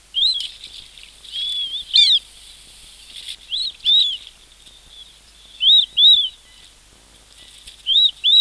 Marbled Duck
Marbled-Duck.mp3